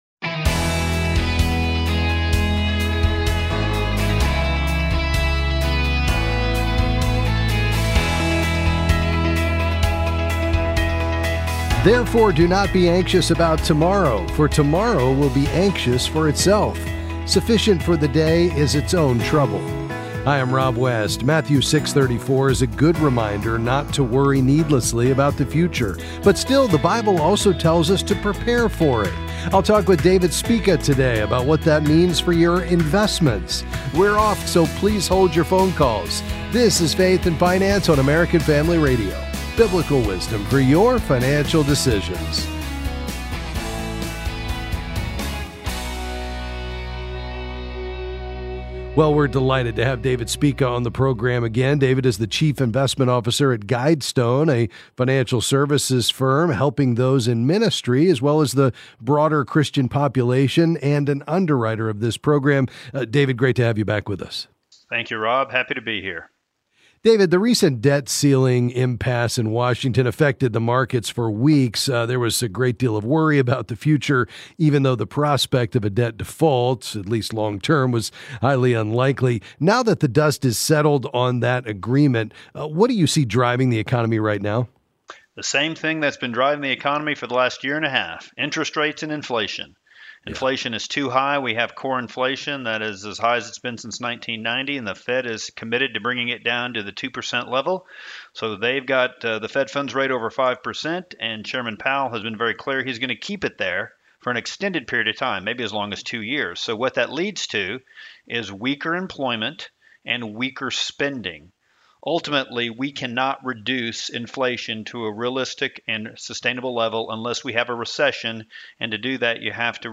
Then he’ll answer calls on various financial topics.